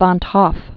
(vänt hôf, hŏf), Jacobus Henricus 1852-1911.